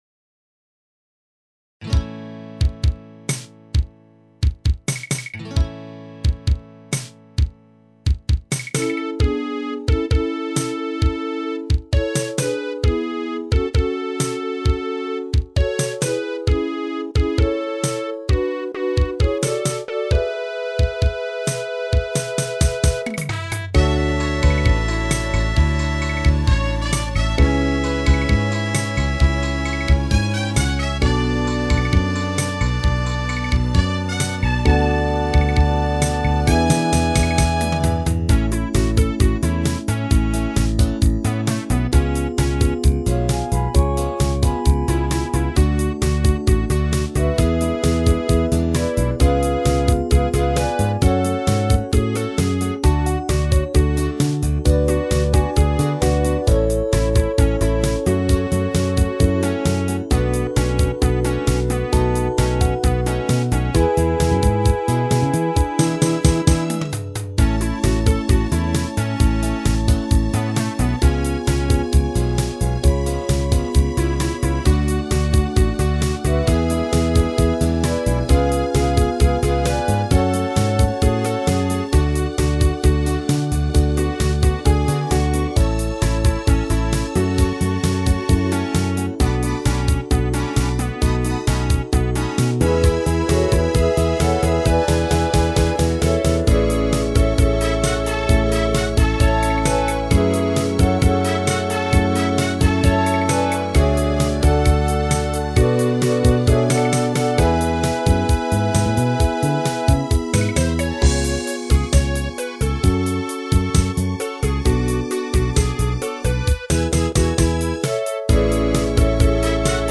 フェイドアウトばかりもつまらないのでエンディングはテキトーに。